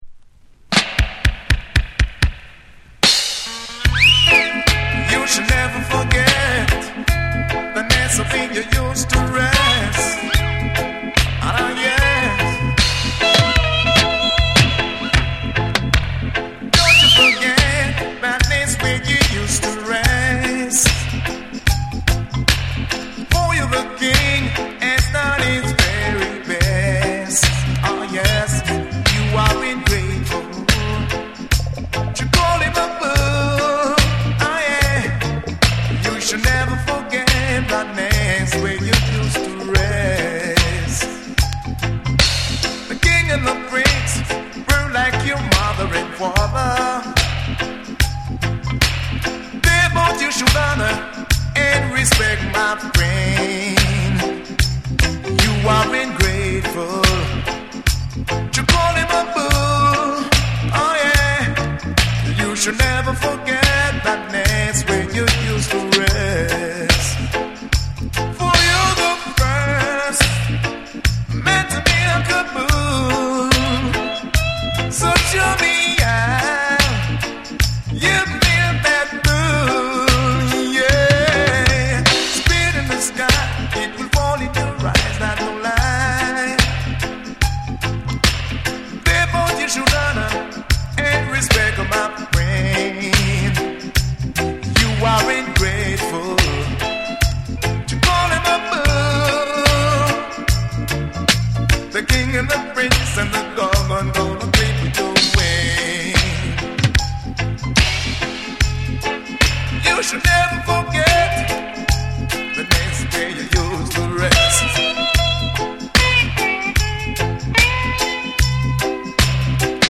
※A4にキズ、プチノイズ入る箇所あり。